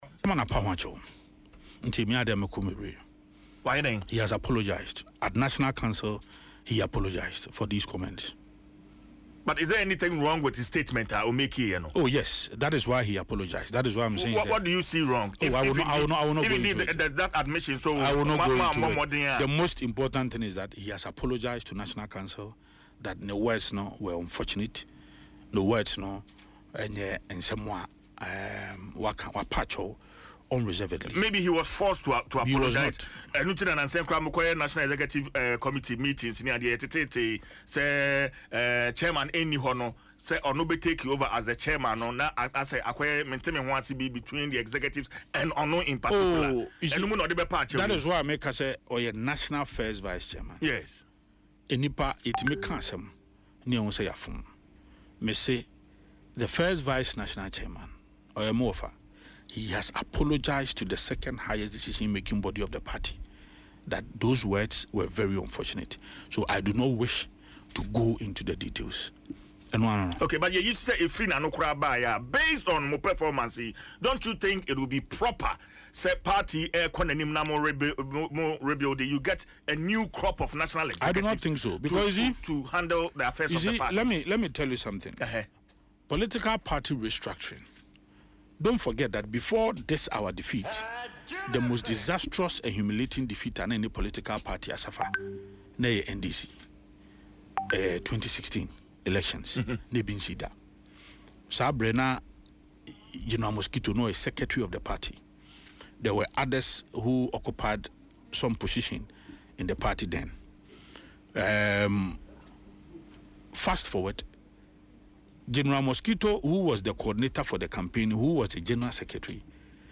The NPP National Organiser, Henry Nana Boakye, popularly known as Nana B, disclosed this in an interview on Adom FM’s Dwaso Nsem.